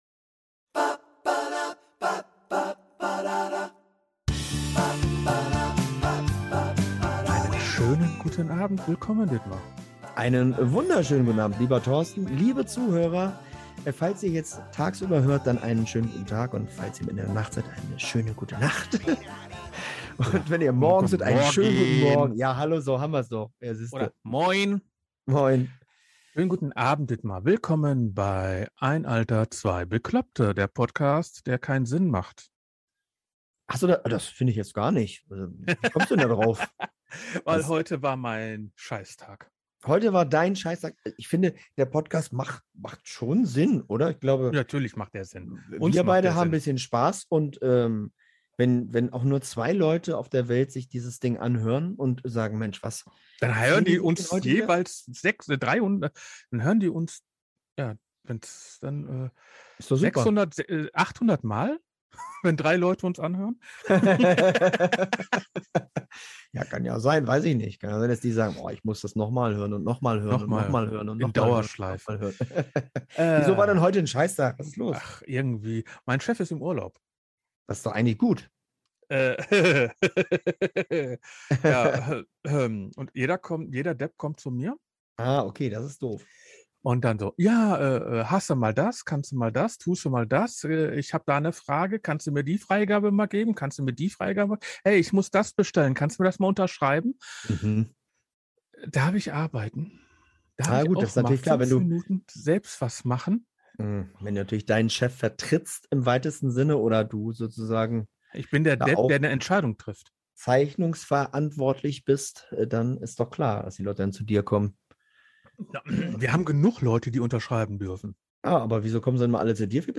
Außerdem: Die beiden Podcaster haben keine neuen Werbe-Melodien im Kopf.